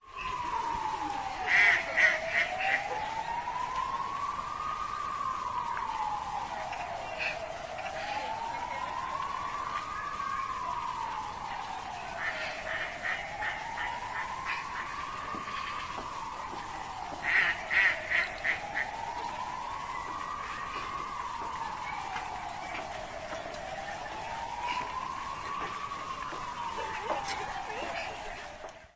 在天桥下进行的现场记录（电车轨道卡车Pestka的天桥）。有轨电车和火车，交通nosie，救护车（有多普勒效应）以及与狗一起散步的人的声音。录音机：缩放h4n与内置麦克风。
标签： 运输 fieldrecording 救护车 动物 波兹南 火车 天桥 铁路 波兰 氛围 电车 吠叫 街道 多普勒
声道立体声